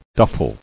[duf·fel]